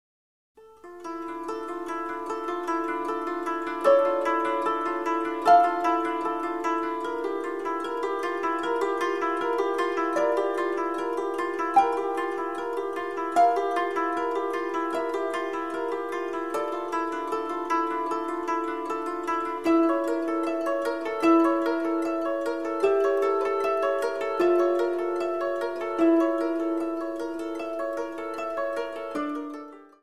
十七弦
尺八
ベース
冴え渡る天空に歌う箏の三連符が懐かしい。